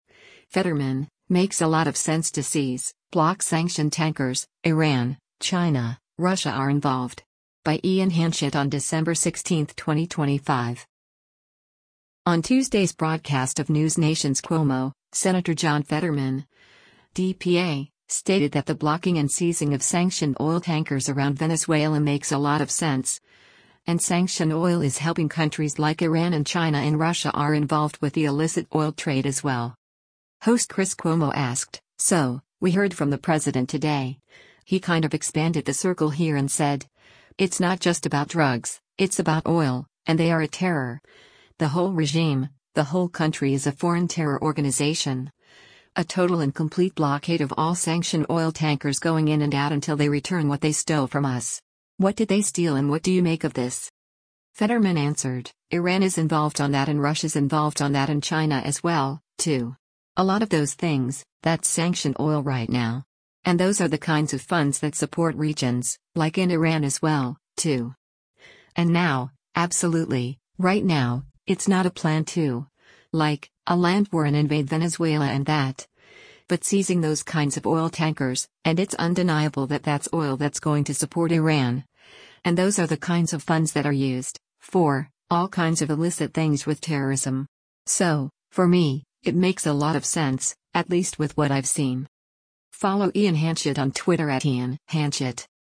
On Tuesday’s broadcast of NewsNation’s “Cuomo,” Sen. John Fetterman (D-PA) stated that the blocking and seizing of sanctioned oil tankers around Venezuela “makes a lot of sense,” and sanctioned oil is helping countries like Iran and China and Russia are involved with the illicit oil trade as well.